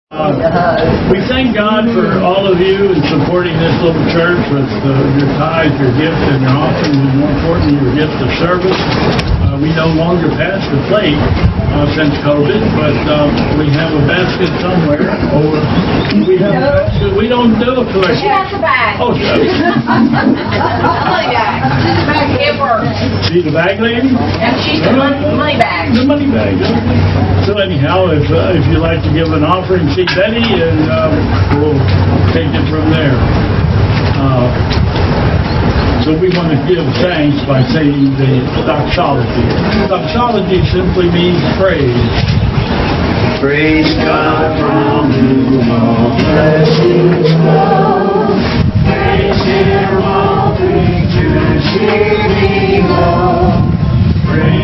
All of the recordings on this page come from videos taken at the service which were too large to put online. As such, they are not very good...with the noise of unshielded wind.